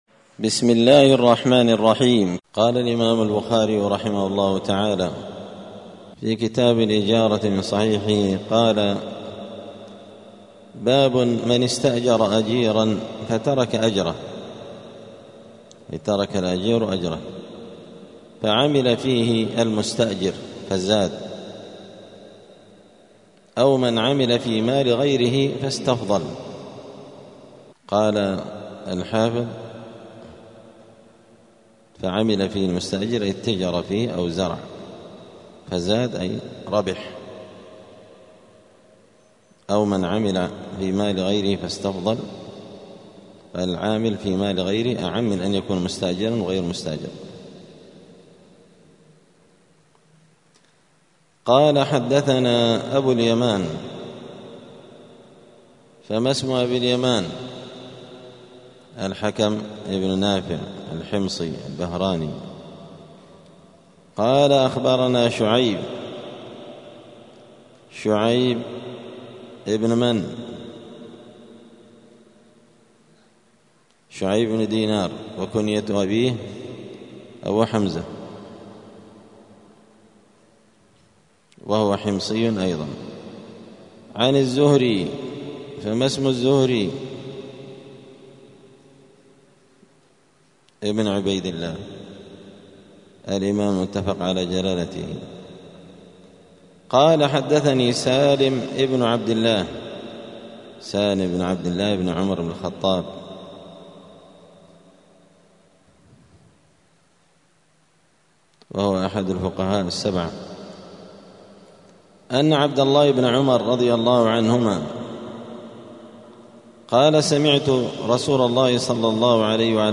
دار الحديث السلفية بمسجد الفرقان قشن المهرة اليمن
الأربعاء 14 محرم 1447 هــــ | الدروس، دروس الحديث وعلومه، شرح صحيح البخاري، كتاب الإجارة من صحيح البخاري | شارك بتعليقك | 4 المشاهدات